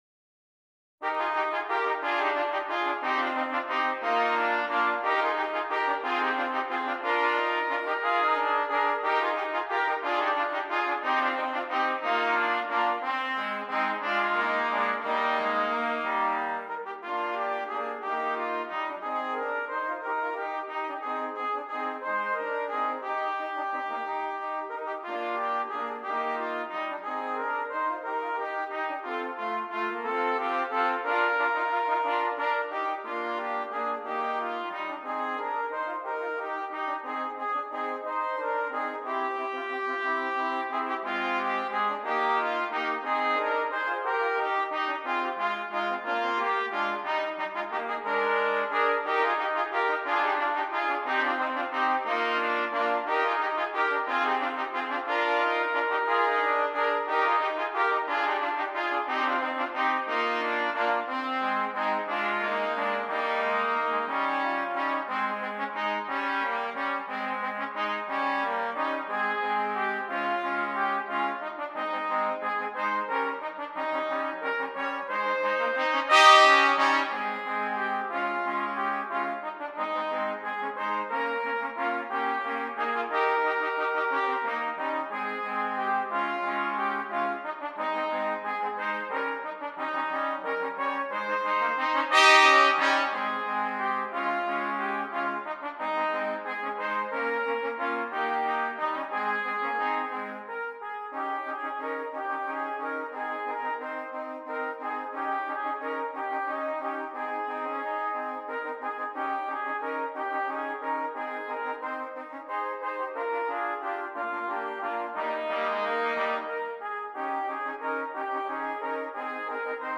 3 Trumpets